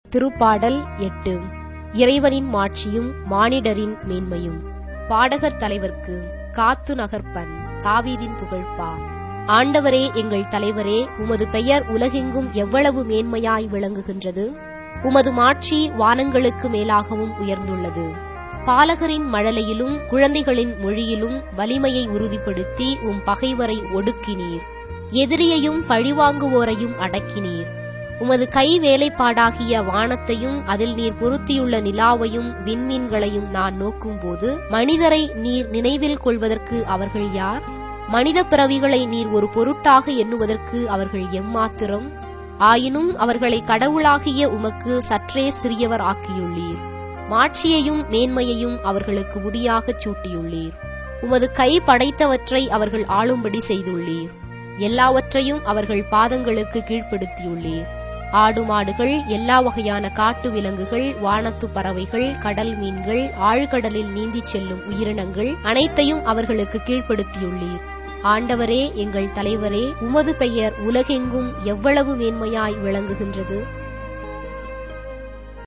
Tamil Audio Bible - Psalms 84 in Ecta bible version